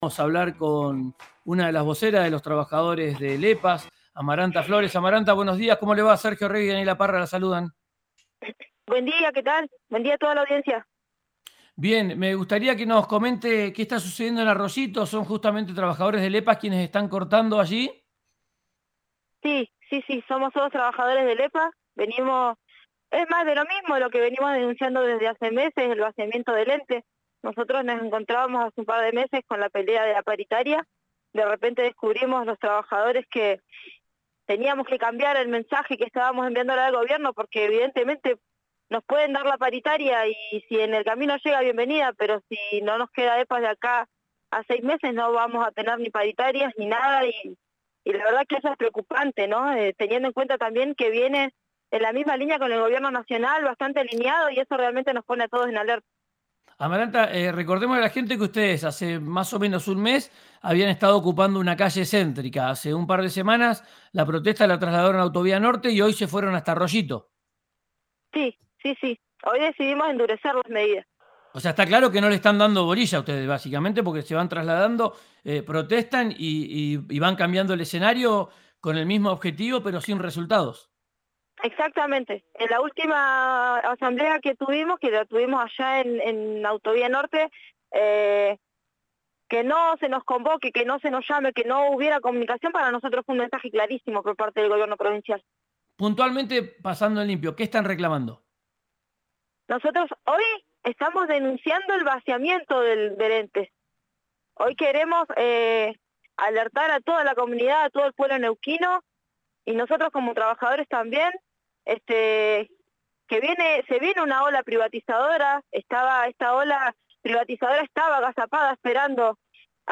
en RÍO NEGRO RADIO